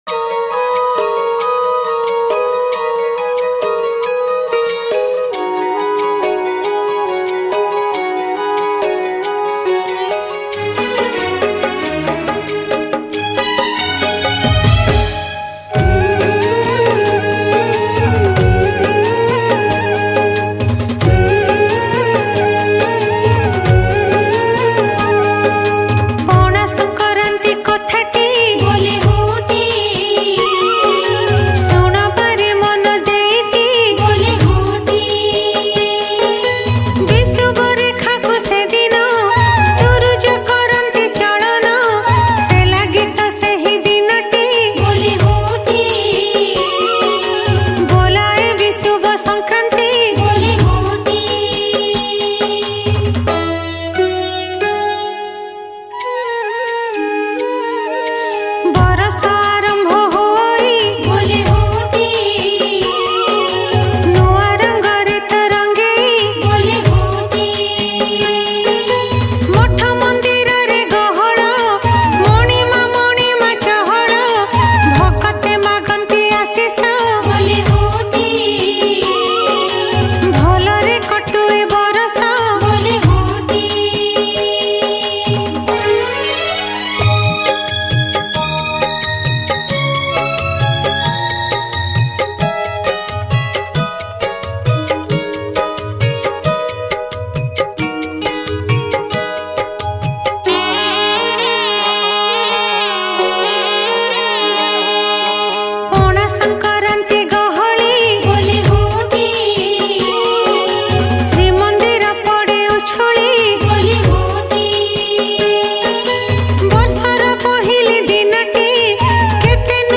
Pana Sankarati Special Bhajan Songs Download